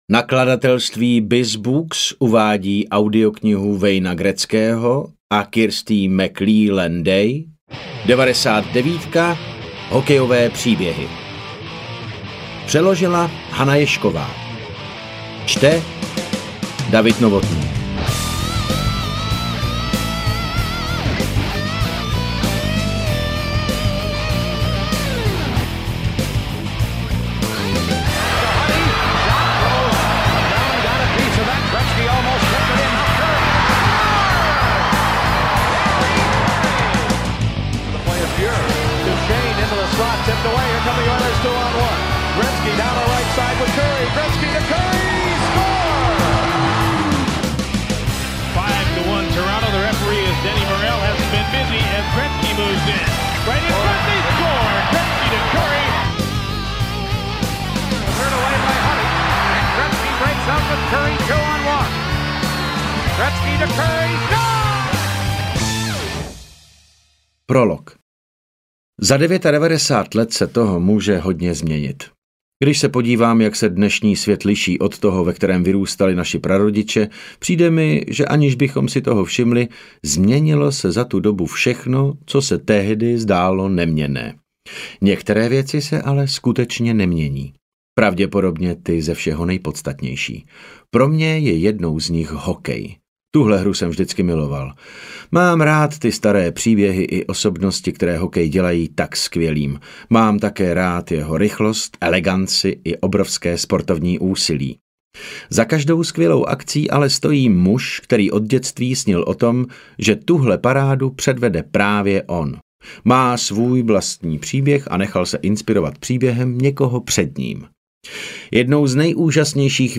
Interpret:  David Novotný
Divadelní a filmový herec, dabér a vynikající interpret audioknih.
AudioKniha ke stažení, 40 x mp3, délka 12 hod. 31 min., velikost 665,0 MB, česky